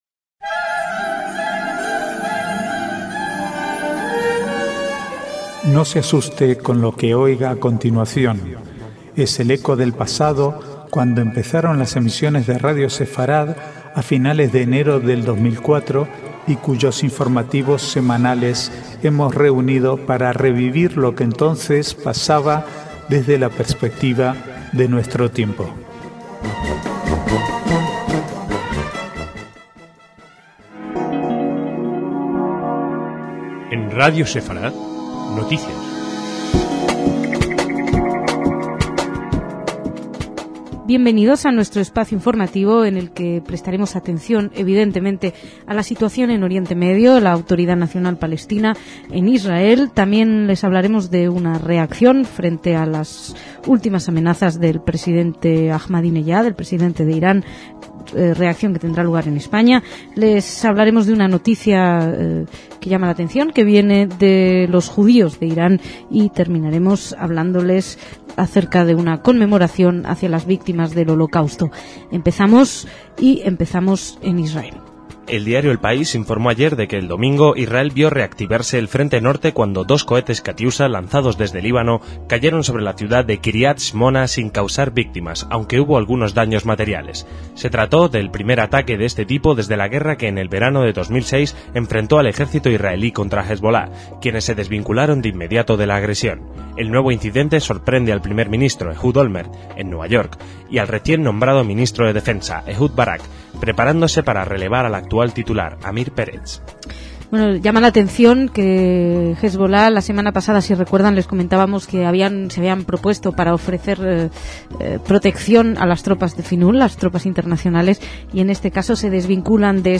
Archivo de noticias del 15 al 20/6/2007